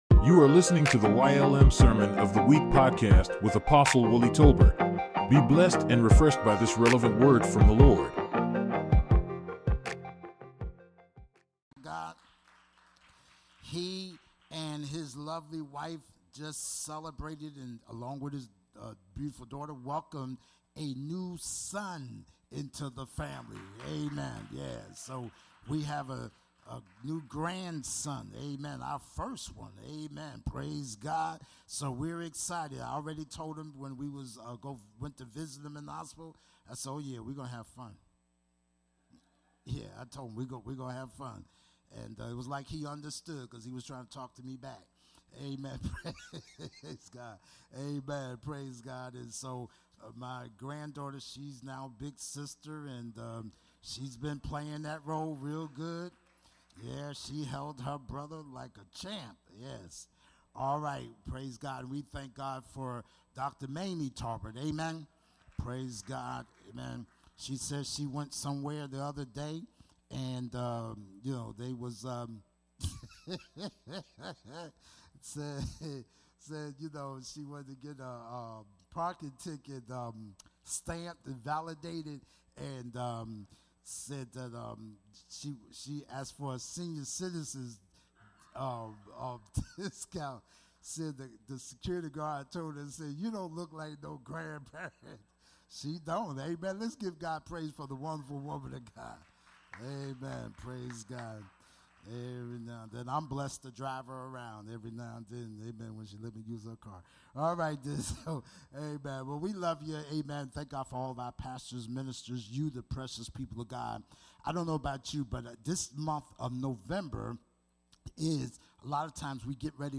YLM Sermon of the Week | Yes Lord' Ministries